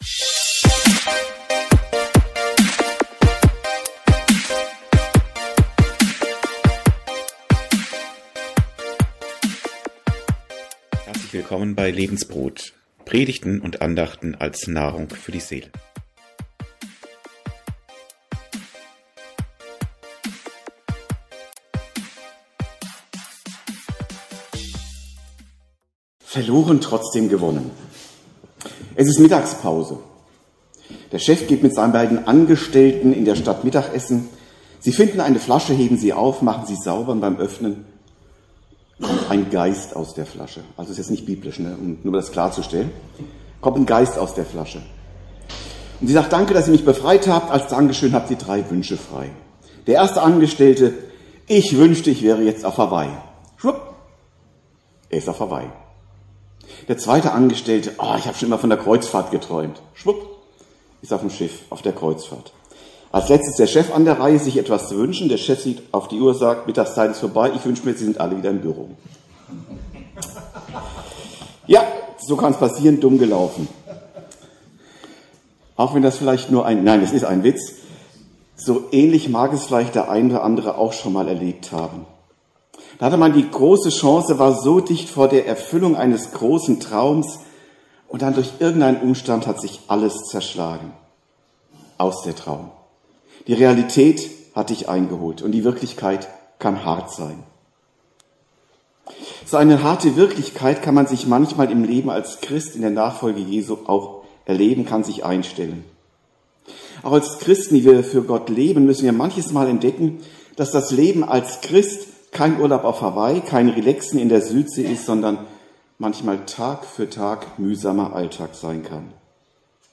Wie alles begann 08 - Verloren und doch gewonnen ~ Predigten u. Andachten (Live und Studioaufnahmen ERF) Podcast
Teil 8 der Reihe "Wie alles begann" (1. Mose 13). Predigt